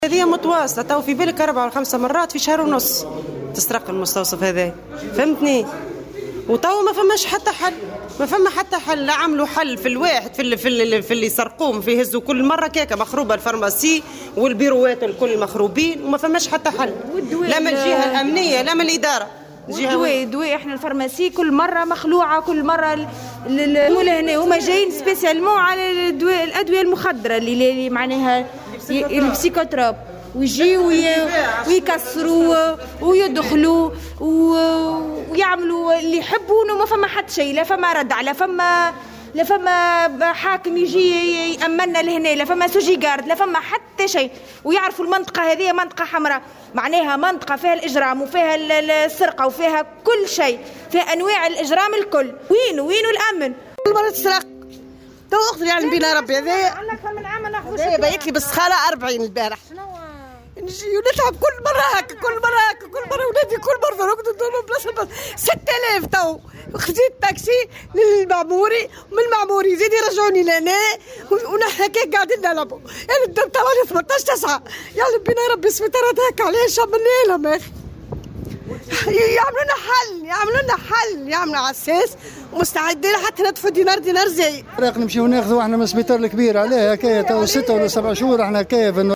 واستنكرت الاطارات شبه الطبية في تصريحات لمراسلة "الجوهرة أف أم" غياب الحماية الامنية، مؤكدين أنهم اصبحوا يعملون تحت التهديد ومطالبين سلطة الإشراف بالتدخل لوضع حد لهذه الاعتداءات المتكررة.